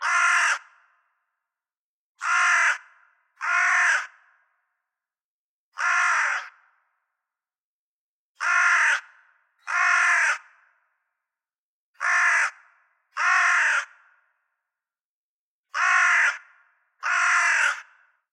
Карканье ворона среди могил